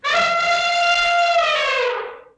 daxiang.mp3